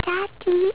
Voiced by: Elizabeth Taylor
daddy.wav